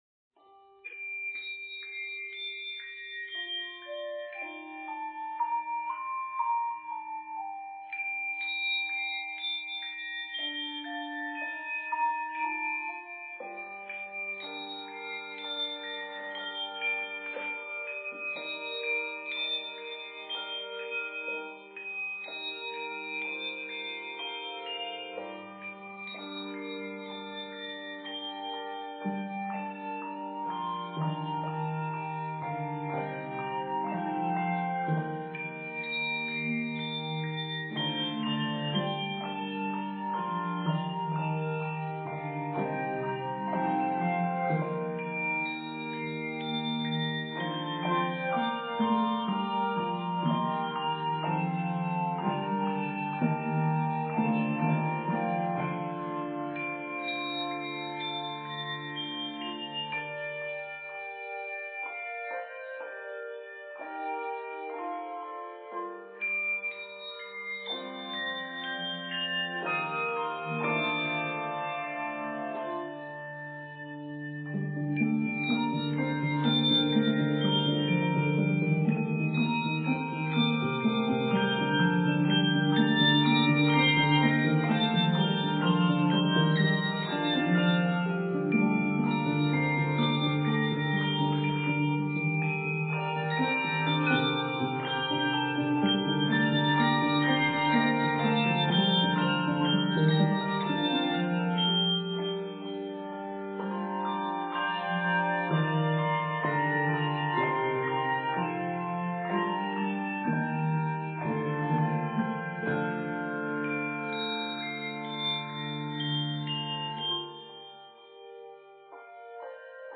hauntingly beautiful setting